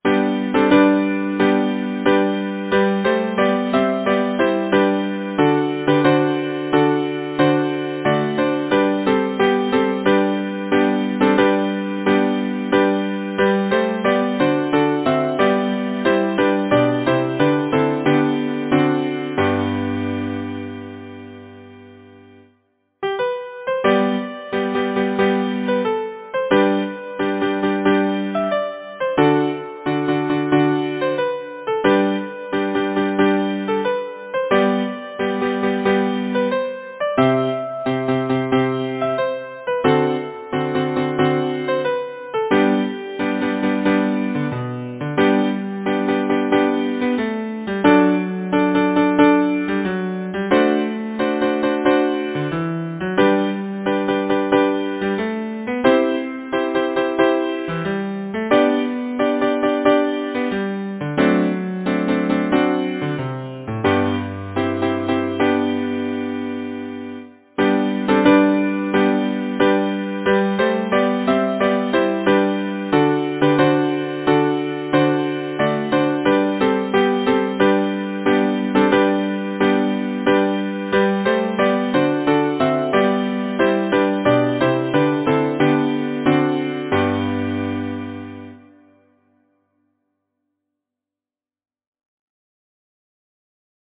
Title: The Rippling River Composer: Oliver L. Fleck Lyricist: Jennie M. Fleck Number of voices: 4vv Voicing: SATB Genre: Secular, Partsong
Language: English Instruments: A cappella